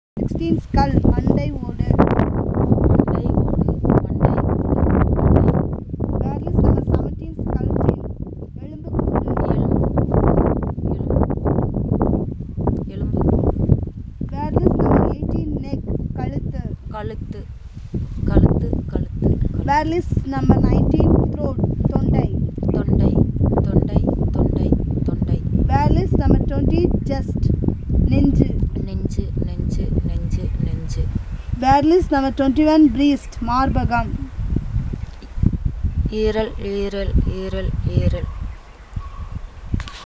Elicitation of words about human body parts - Part 6